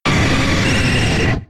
Cri de Nidoking K.O. dans Pokémon X et Y.